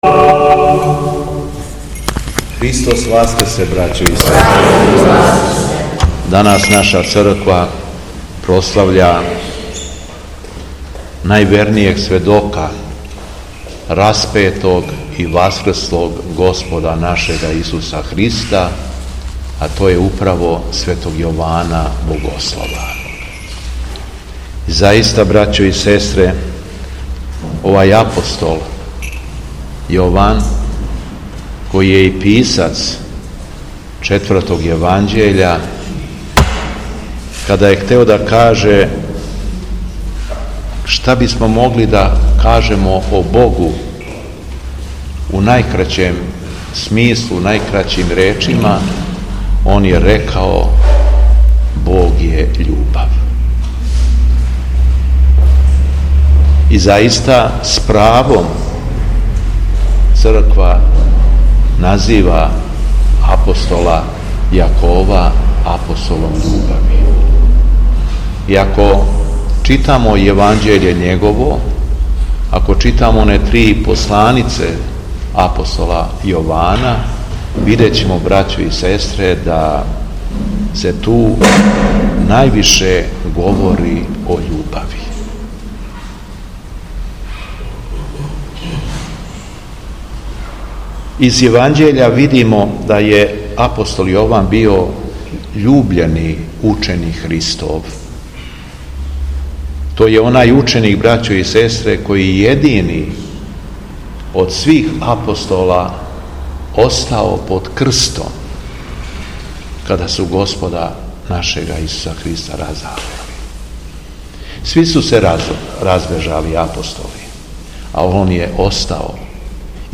У уторак, 21. маја 2024. године, када Црква прославља Светог апостола Јована Богослова и Светог свештеномученика Недељка Раковачког, Његово Преосвештенство Епископ шумадијски господин Јован служио је Свету Литургију у храму Светог великомученика Пантелејмона, у крагујевачком насељу Станово.
Беседа Његовог Преосвештенства Епископа шумадијског господина Јована
Након прочитаног јеванђељског зачала, Епископ Јован се обратио верном народу, рекавши: